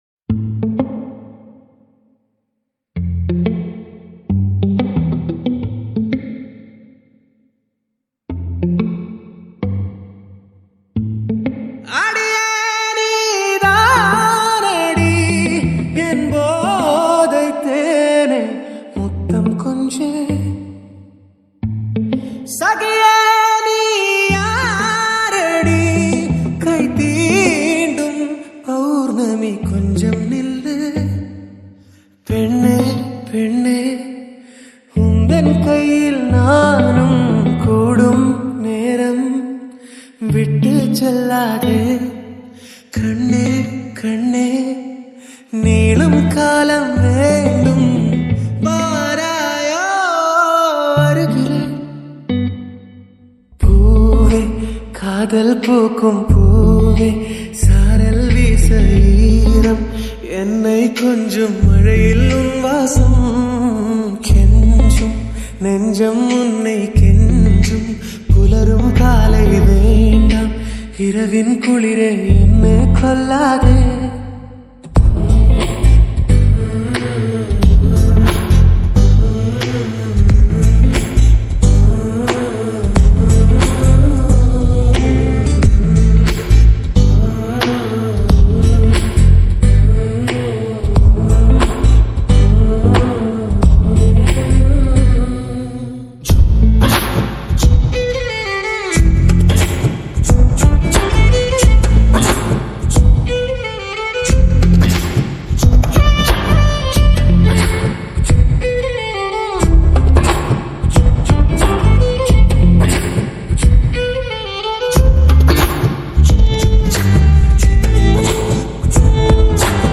ALL TAMIL DJ REMIX » Tamil 8D Songs